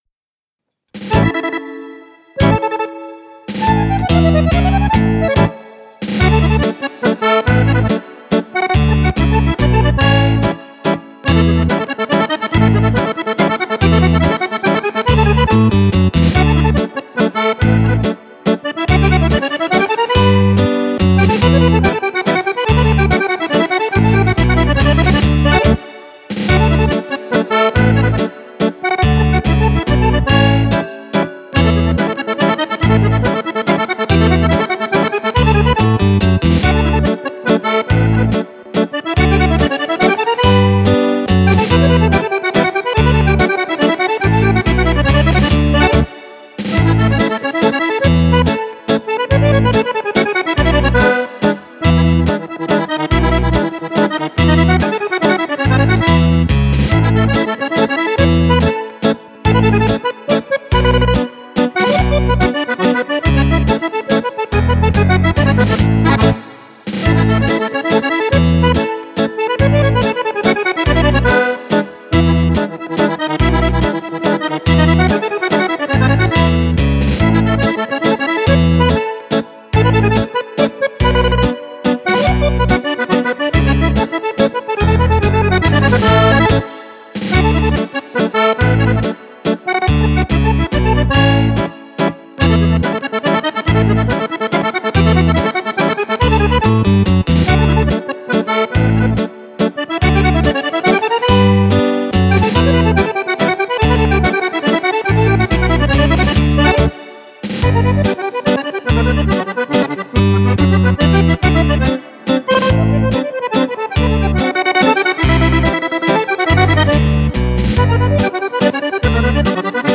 Mazurka
primo CD di Fisarmonica solista.